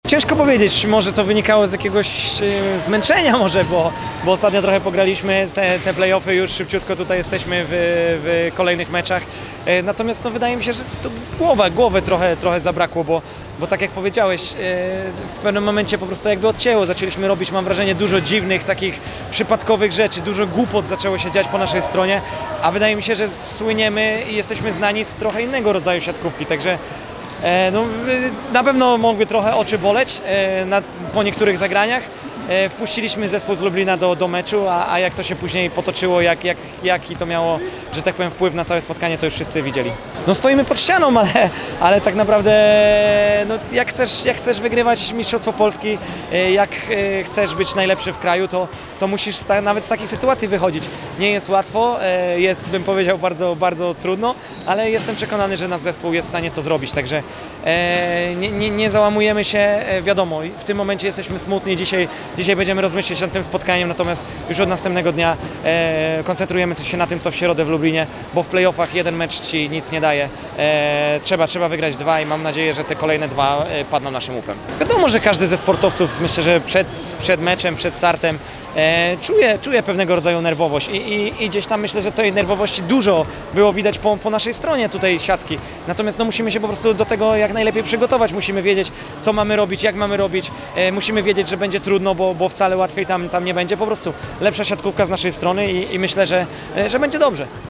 Powiedział libero drużyny JSW Jastrzębskiego Węgla — Jakub Popiwczak.